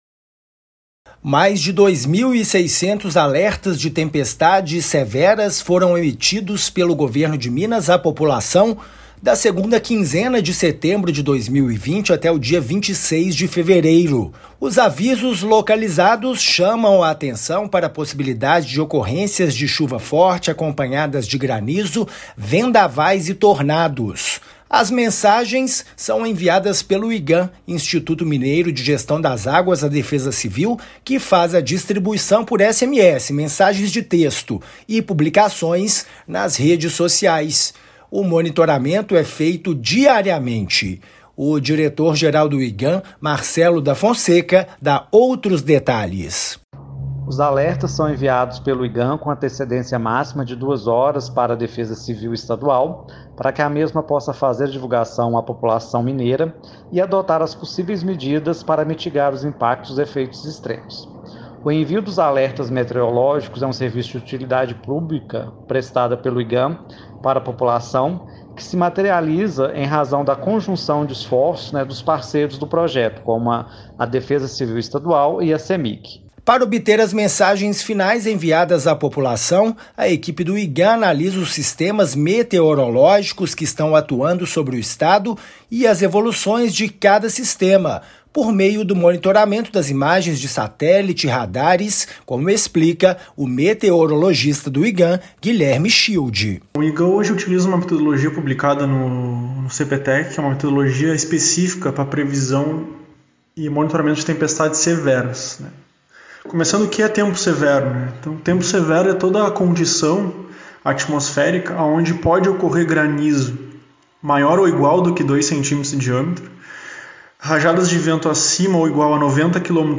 [RÁDIO] Equipes do governo mineiro identificam ocorrência de tempestades e vendavais
Já foram enviados 2,6 mil alertas à população no período chuvoso, desde a segunda quinzena de setembro de 2020. Ouça matéria de rádio.